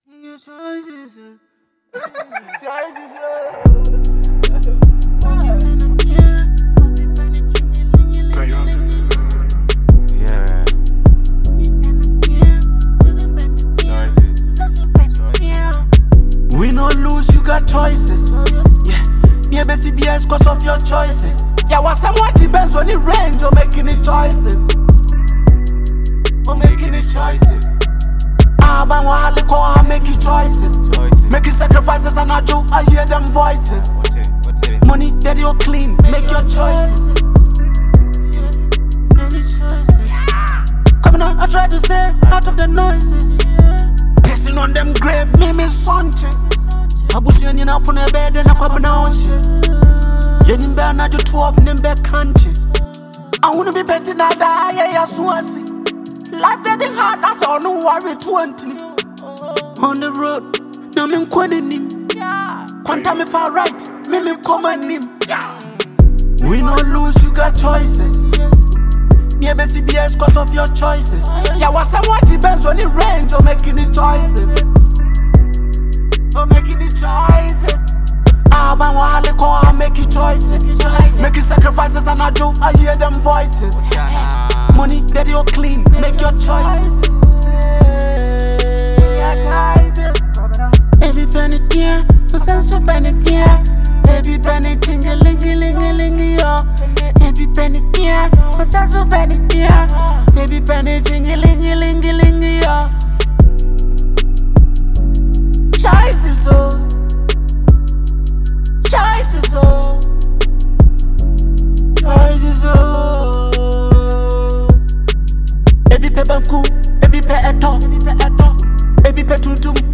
a reflective and thought-provoking Ghanaian hip-hop record
Genre: Hip-Hop / Conscious Rap